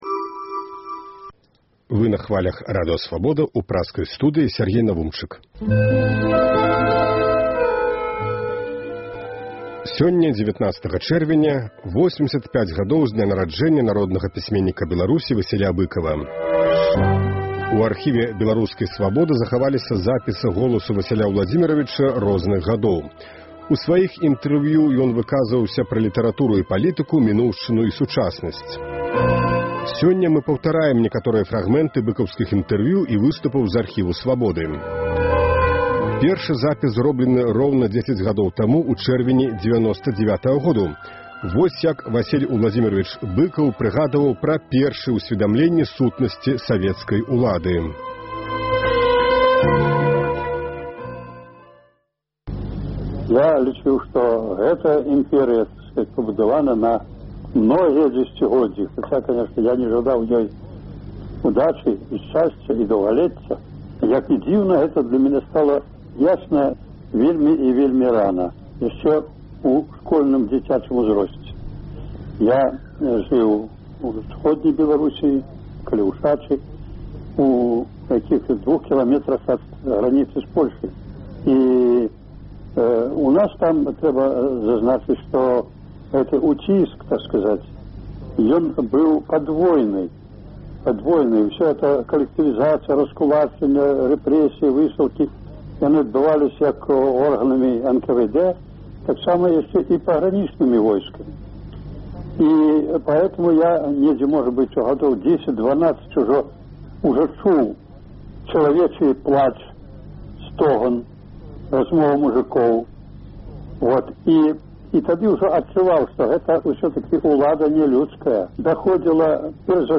У архіве беларускай Свабоды захаваліся запісы голасу Васіля Ўладзіміравіча Быкава розных гадоў. У сваіх інтэрвію ён выказваўся пра літаратуру і палітыку, мінуўшчыну і сучаснасьць. Сёньня – у дзень 85-годзьдзя пісьменьніка – выбраныя фрагмэнты інтэрвію і выступаў розных гадоў.